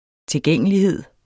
Udtale [ teˈgεŋˀəliˌheðˀ ]